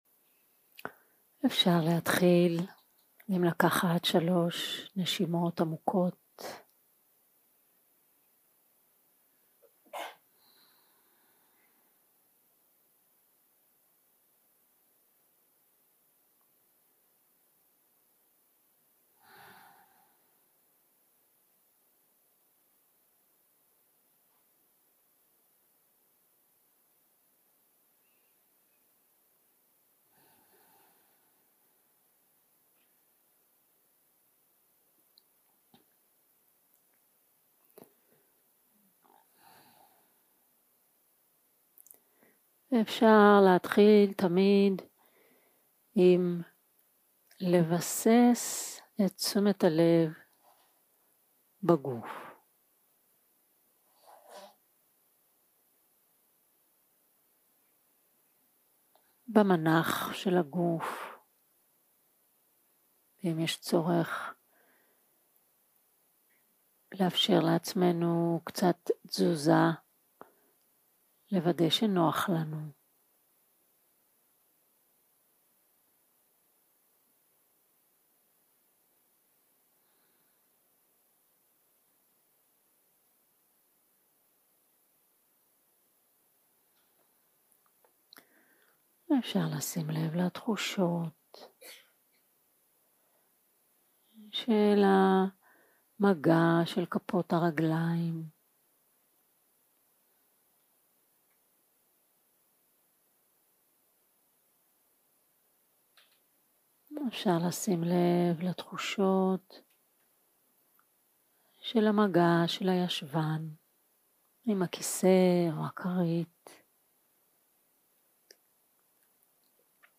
יום 3 - הקלטה 6 - צהרים - מדיטציה מונחית
סוג ההקלטה: מדיטציה מונחית